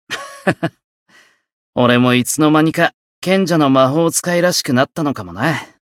觉醒语音 哈哈…从什么时候开始，我也变得像个贤者的魔法使了呢 はは…俺もいつの間にか、賢者の魔法使いらしくなったのかな 媒体文件:missionchara_voice_474.mp3